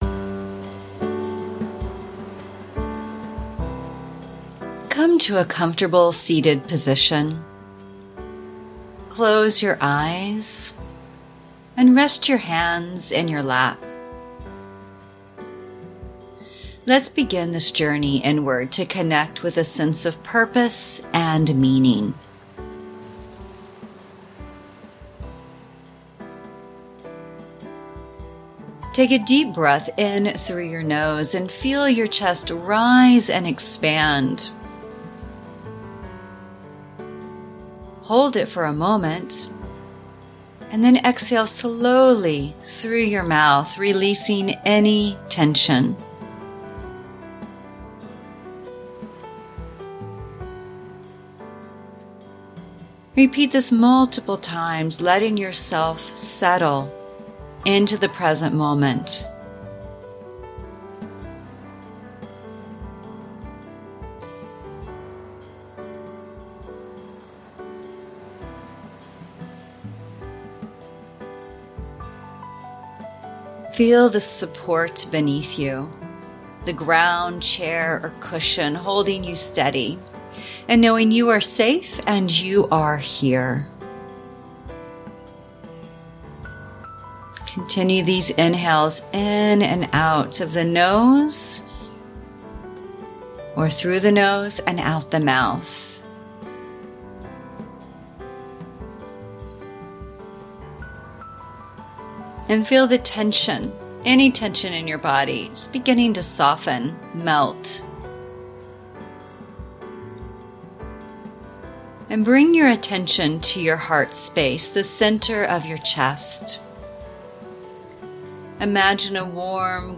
Private Guided Meditation
a guided meditation on meaning.